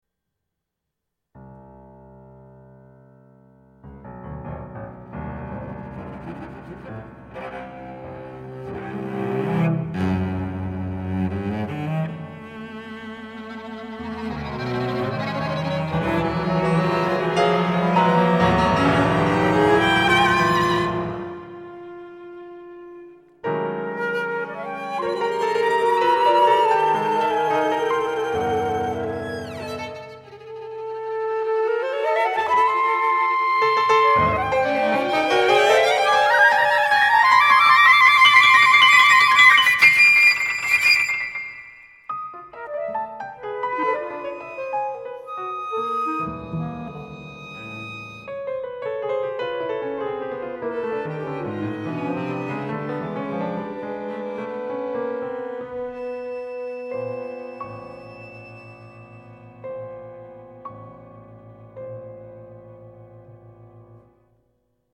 flauta, clarinete, violino, violoncelo e piano